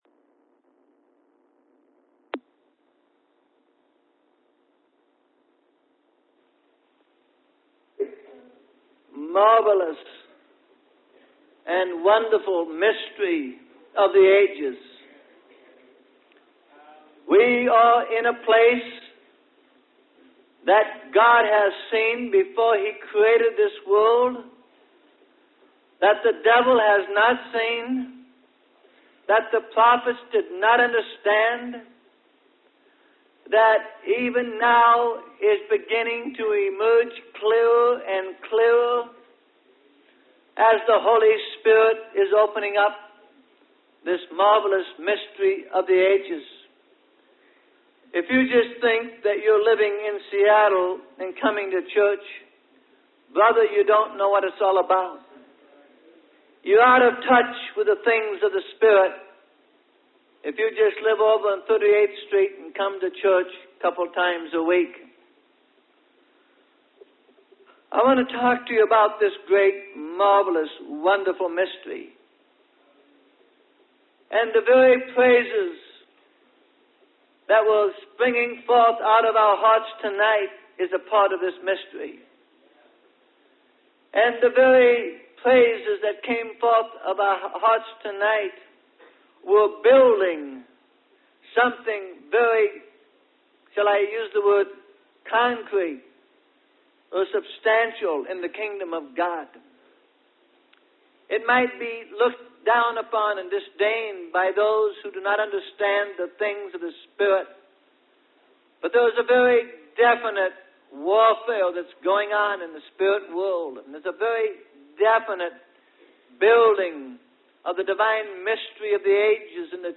Sermon: The Kingdom Of God Is At Hand.